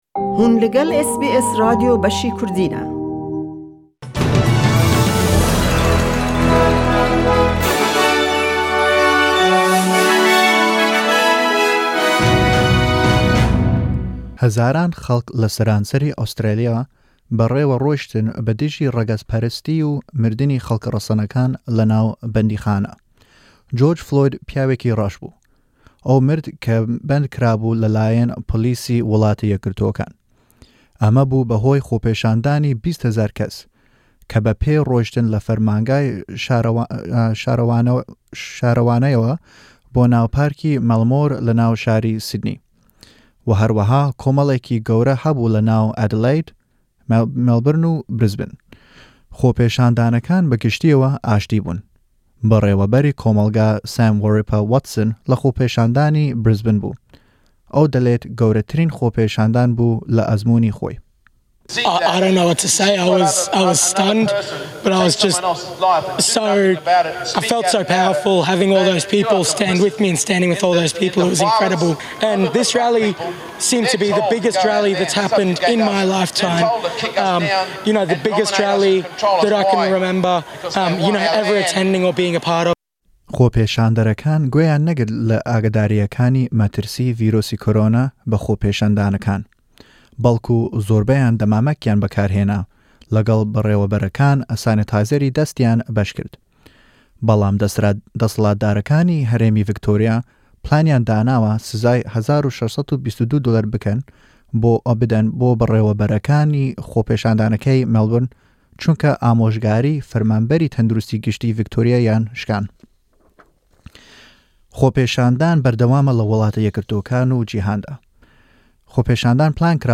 Nûçeyên Rojî Yekşemê 07/06/2020